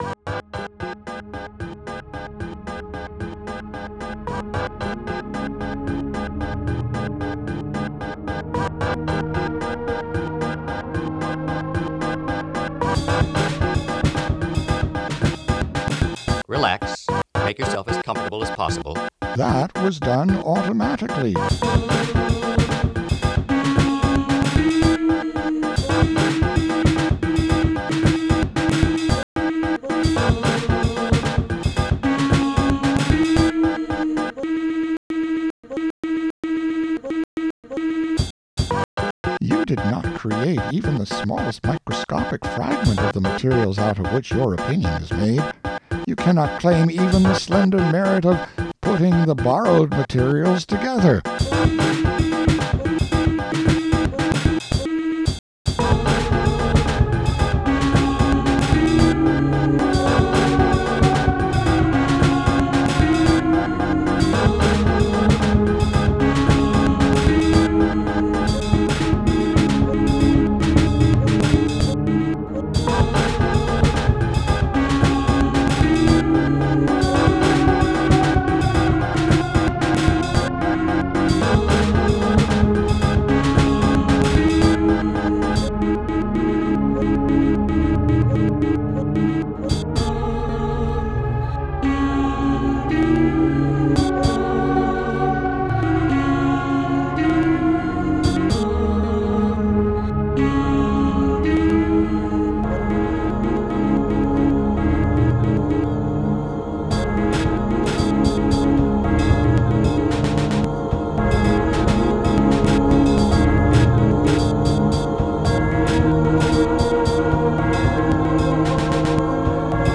collage music